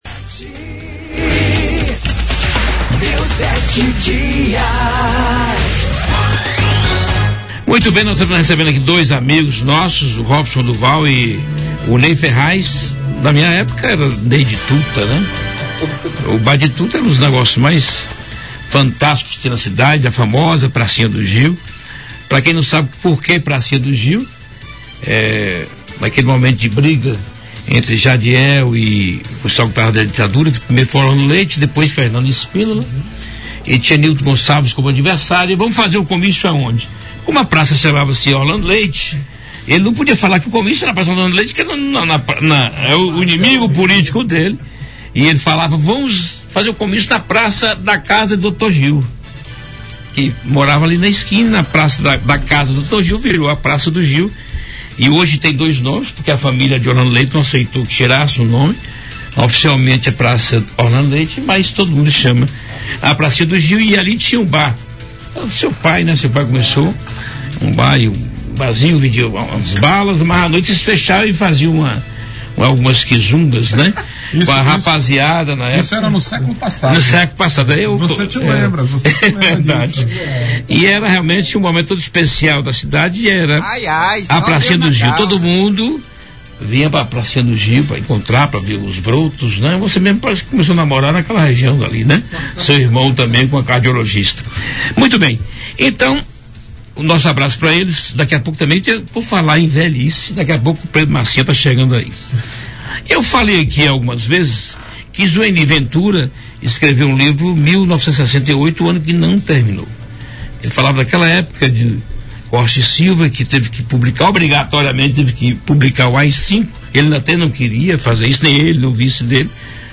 Eleições 2016: PT aposta na comissão para evitar prévias em Vitória da Conquista; ouça a entrevista